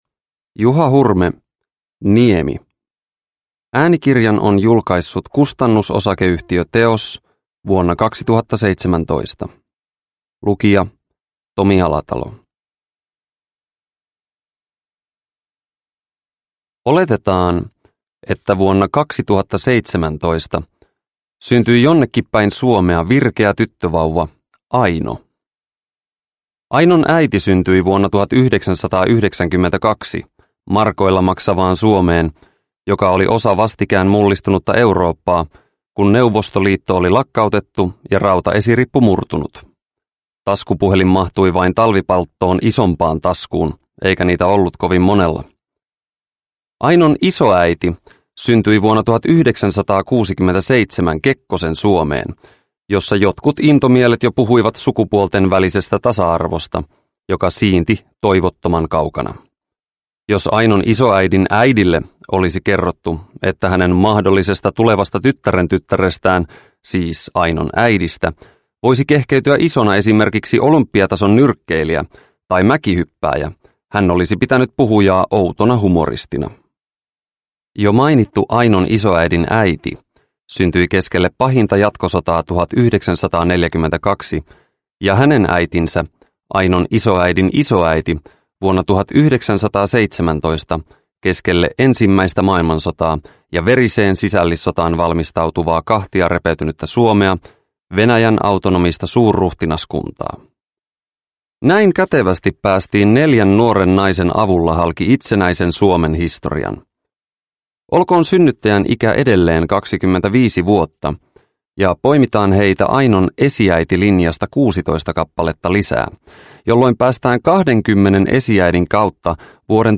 Niemi – Ljudbok – Laddas ner